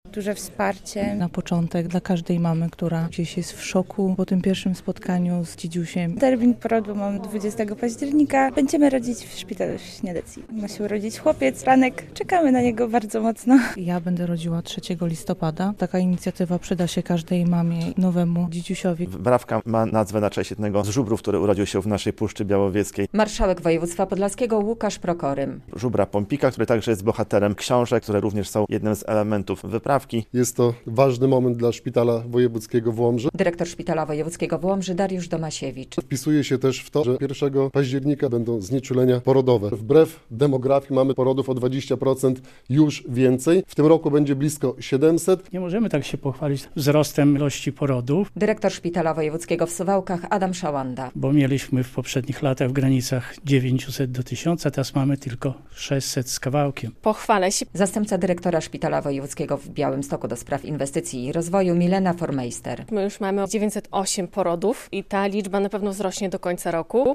Pierwsza podlaska wyprawka dla dziecka - relacja